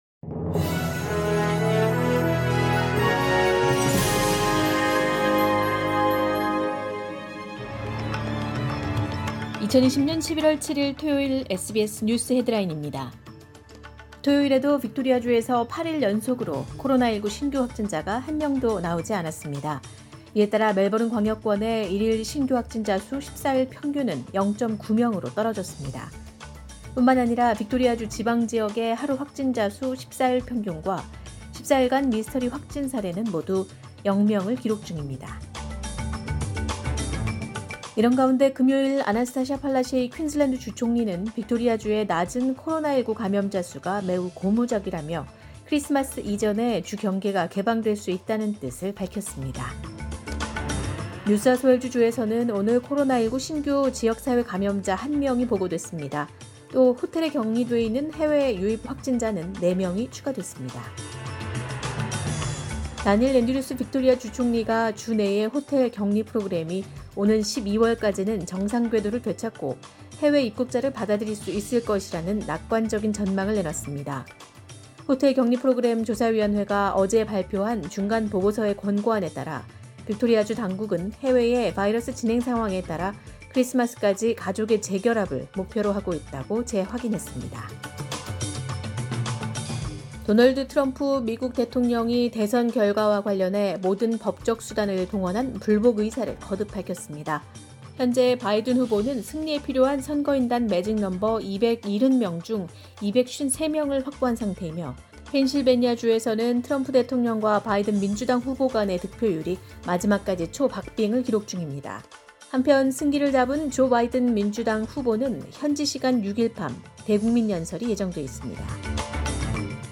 2020년 11월 7일 토요일 오전의 SBS 뉴스 헤드라인입니다.